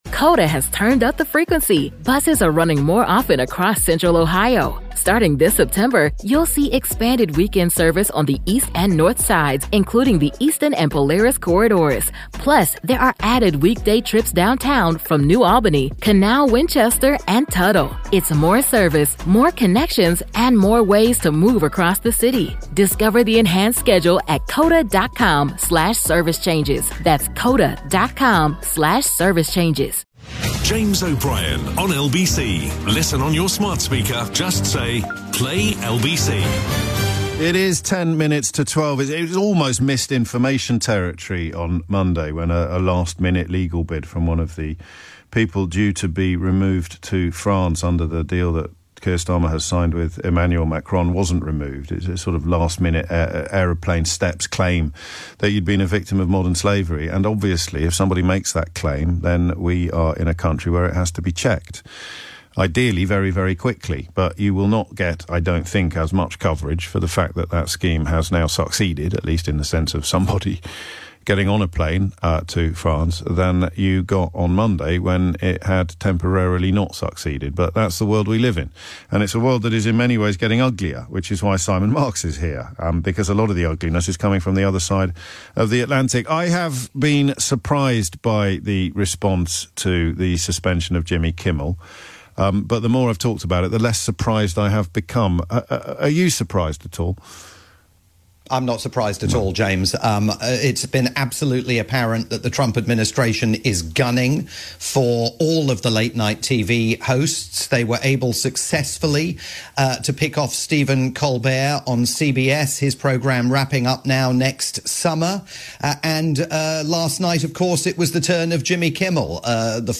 live update